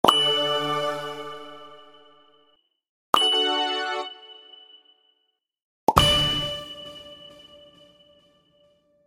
1. Звук перехода на новый уровень: громкий щелчок и фанфары n2. Эпичный звук level up: мощный удар и хрустальный звон n3. Мягкий звук повышения уровня: нежный перезвон и шепот ветра